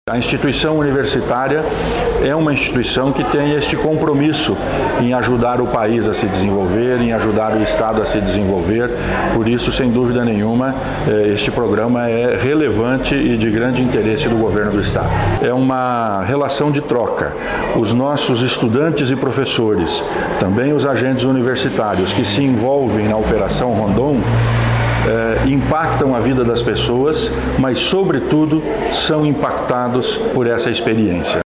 Sonora do secretário da Ciência, Tecnologia e Ensino Superior, Aldo Bona, sobre a Operação Rondon Paraná 2024 | Governo do Estado do Paraná
Sonora do secretário da Ciência, Tecnologia e Ensino Superior, Aldo Bona, sobre a Operação Rondon Paraná 2024